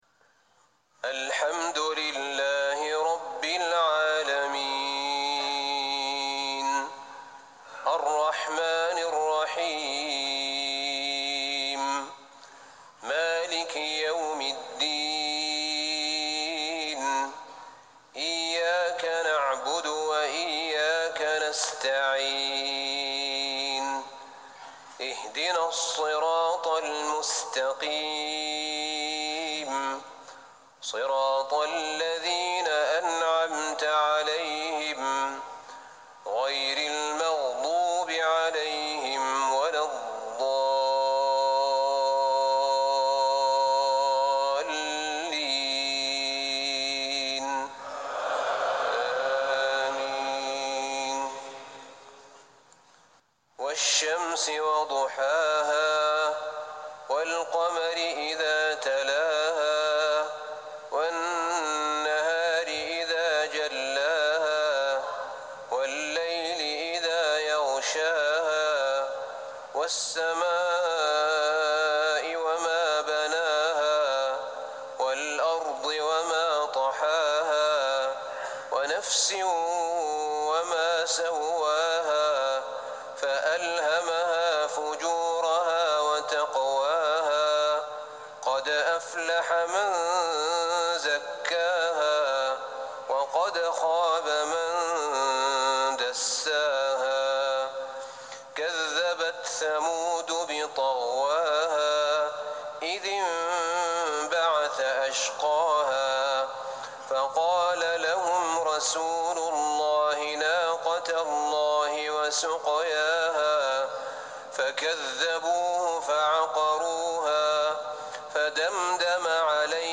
صلاة العشاء 29 رجب 1440هـ سورتي الشمس و الليل | lsha 5-4-2019 prayer from Surah Ash-Shams and Al-Lail > 1440 🕌 > الفروض - تلاوات الحرمين